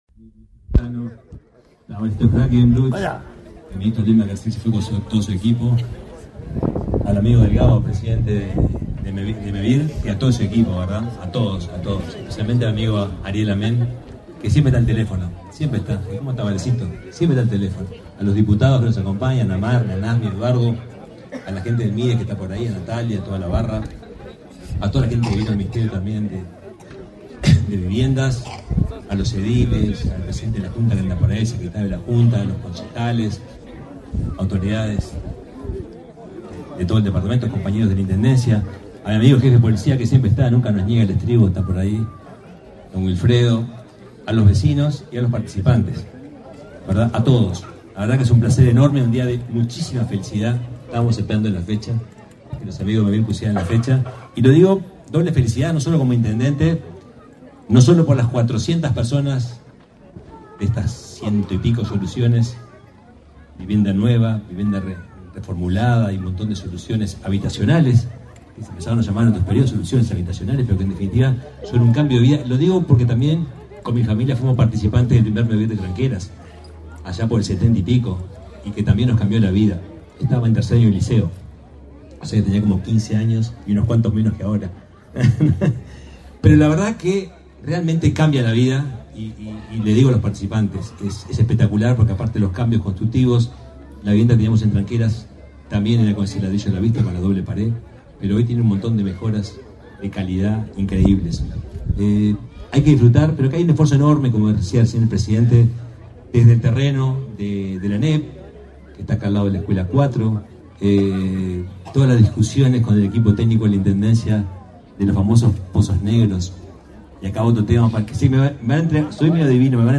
Acto por la inauguración de viviendas de Mevir en Minas de Corrales
En el evento, realizado este 19 de octubre, disertaron el ministro de Vivienda, Raúl Lozano; el presidente de Mevir, Juan Pablo Delgado, y el intendente de Rivera, Richard Sander.